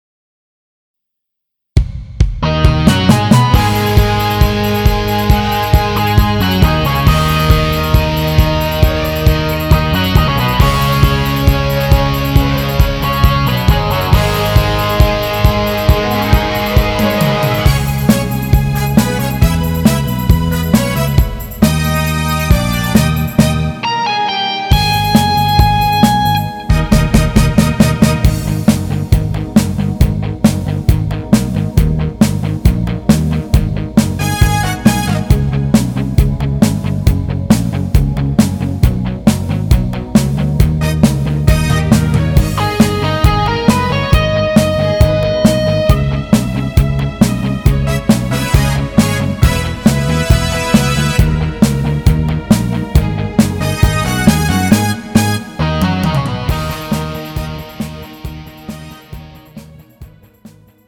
음정 -2키
장르 가요 구분 Pro MR